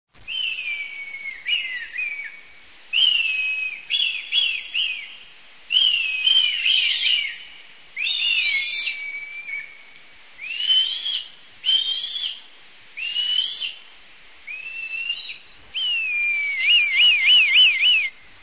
Canto del milano real
cantoMilanoReal.mp3